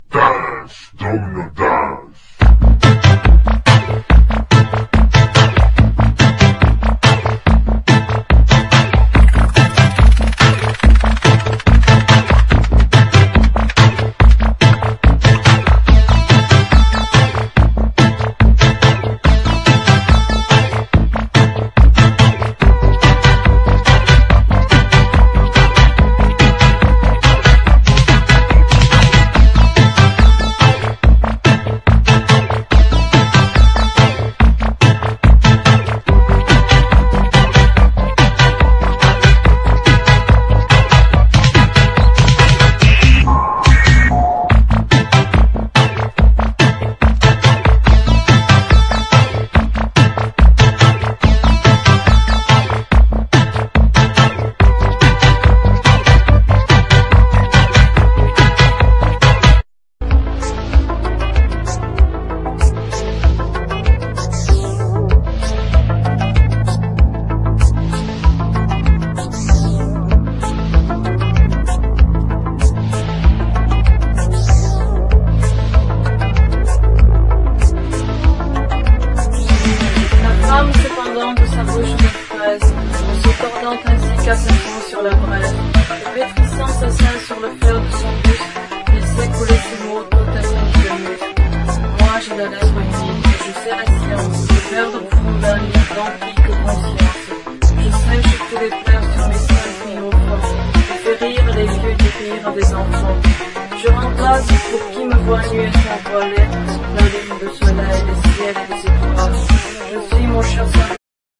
ROCK / 70'S / COSMIC / BALEARIC